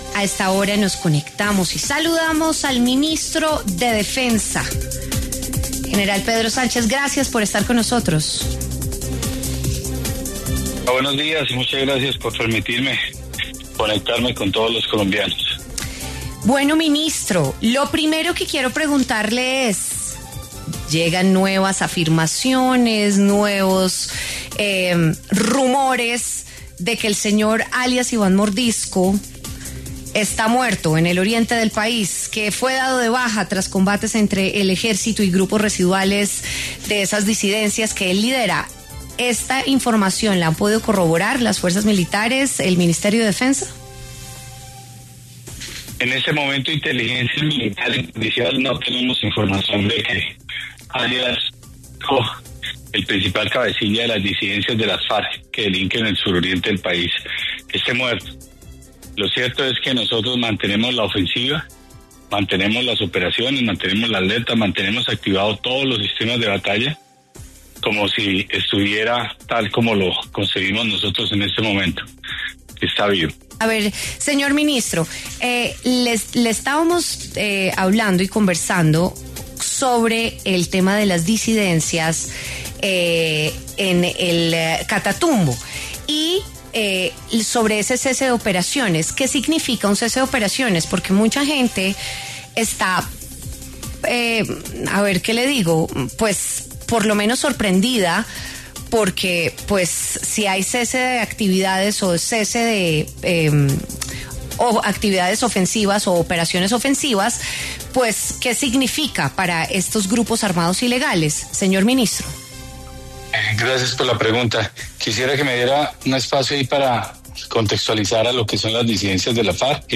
El ministro de Defensa, Pedro Sánchez, habló en W Fin de Semana sobre las operaciones contra las disidencias de las Farc y los recientes atentados que han cometido.